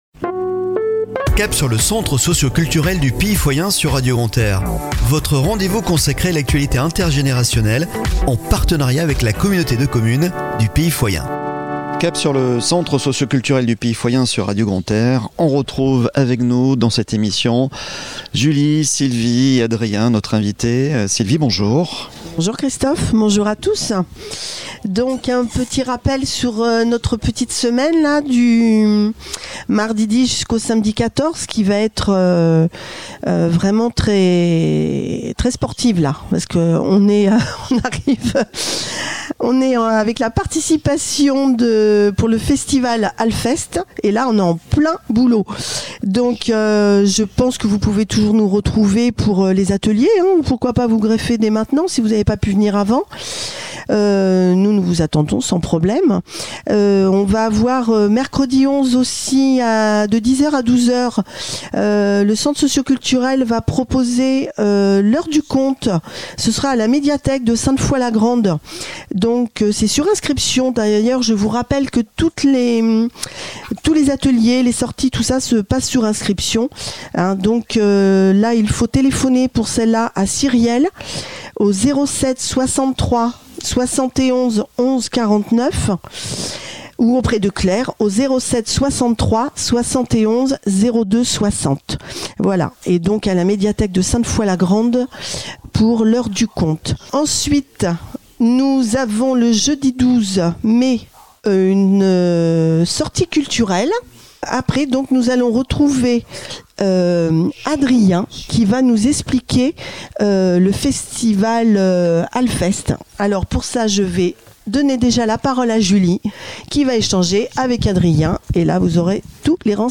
A écouter chaque Lundi à 09h30 et 17h30 , et Mercredi à 12h30 et 19h30 sur Radio Grand "R" en partenariat avec la Communauté de Communes du Pays Foyen